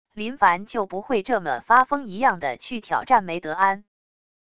林凡就不会这么发疯一样的去挑战梅德安wav音频生成系统WAV Audio Player